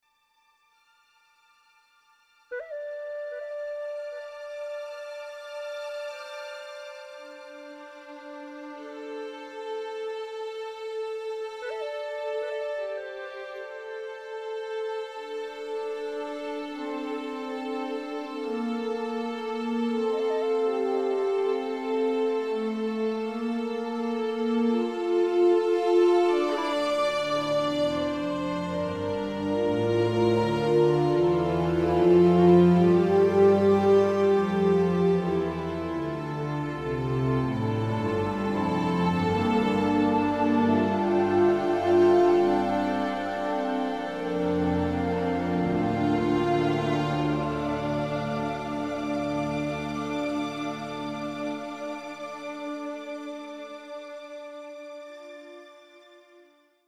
Musik MP3